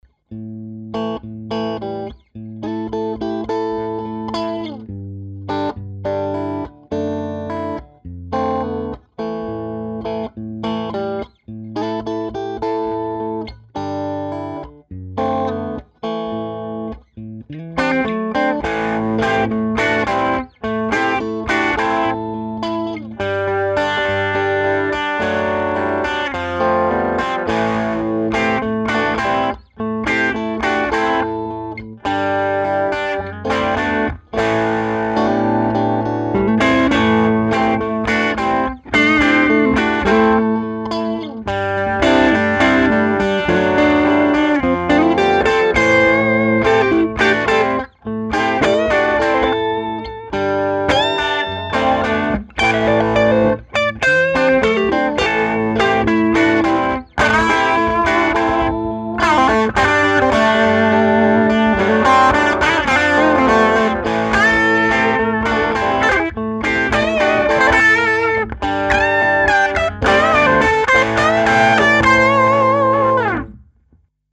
SM57Mix5E3.mp3